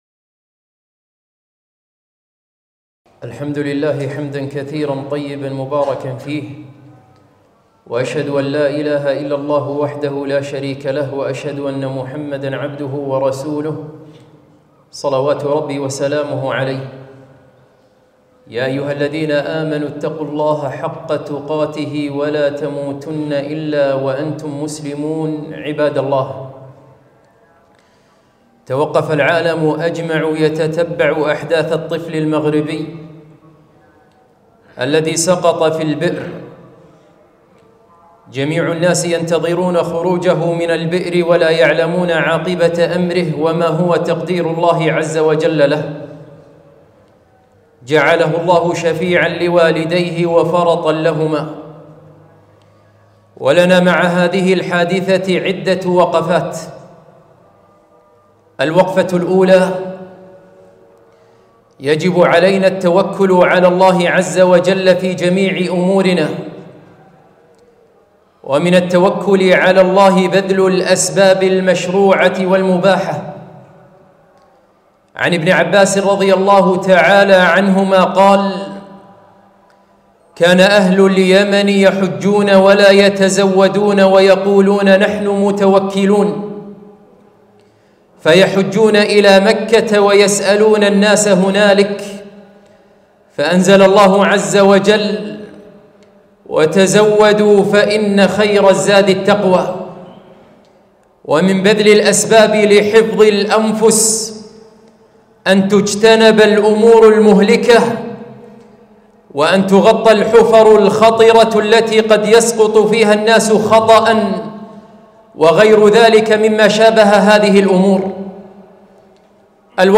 خطبة - وقفات مع حادثة الطفل ريان